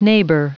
Vous êtes ici : Cours d'anglais > Outils | Audio/Vidéo > Lire un mot à haute voix > Lire le mot neighbour
Prononciation du mot : neighbour